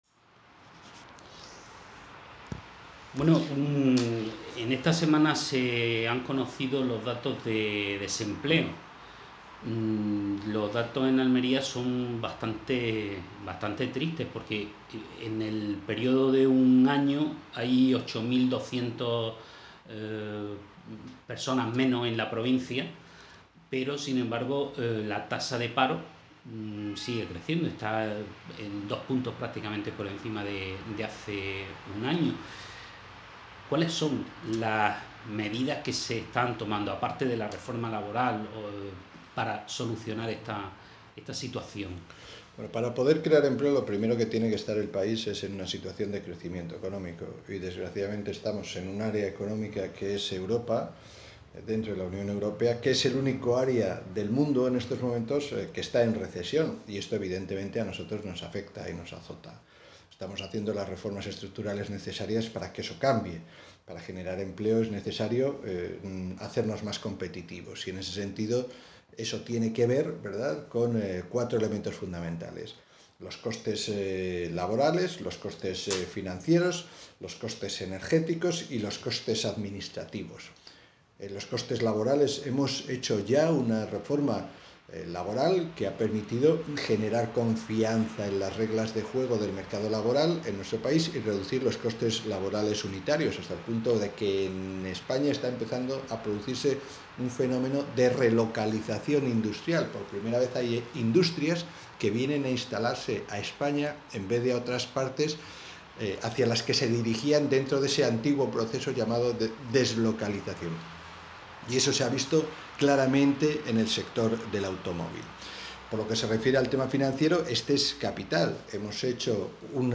hernandoentrevista.mp3